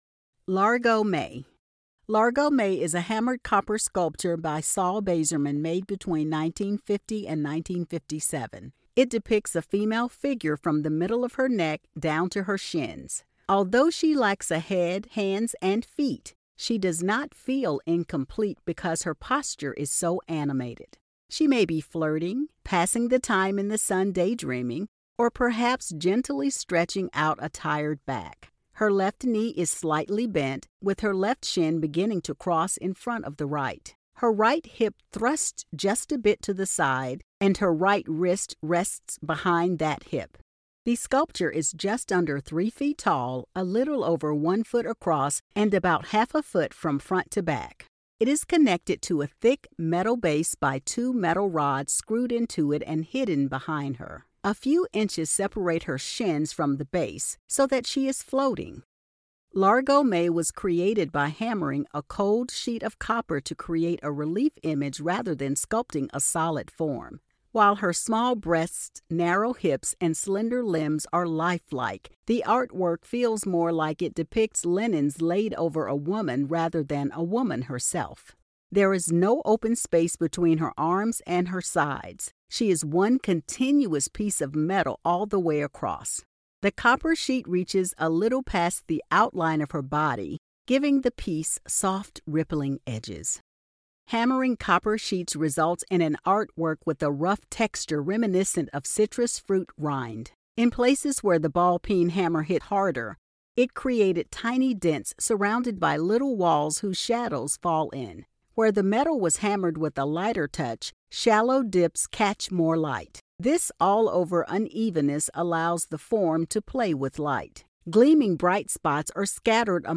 Audio Description (02:24)